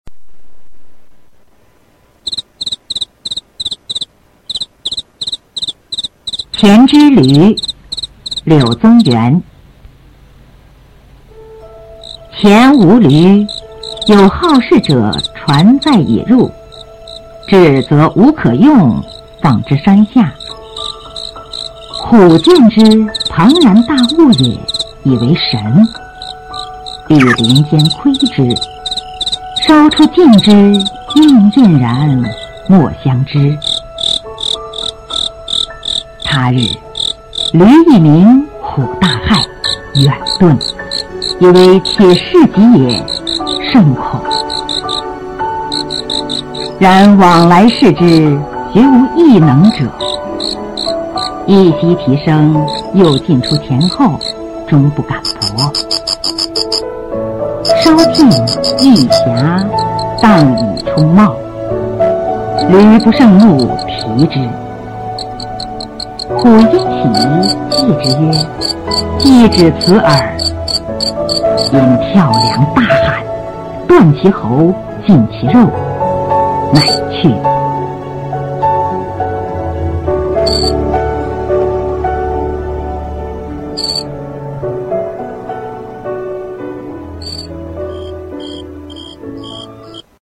柳宗元《黔之驴》原文和译文（含Mp3朗读）